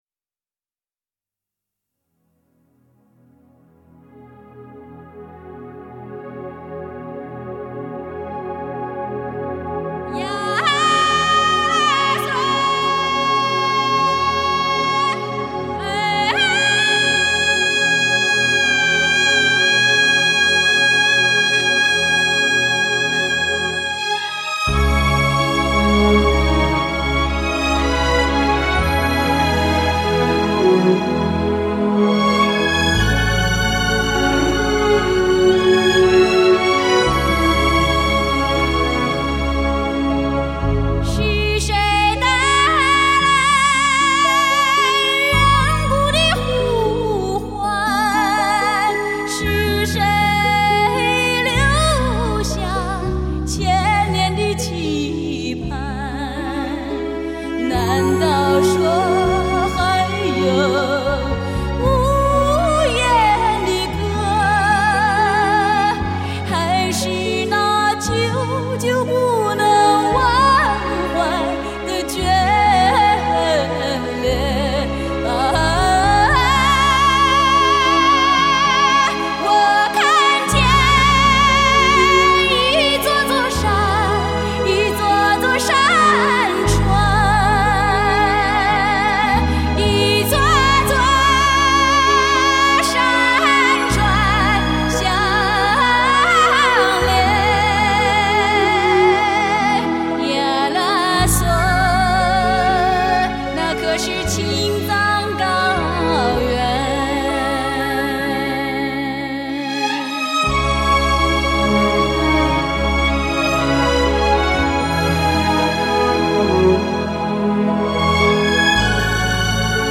女声独唱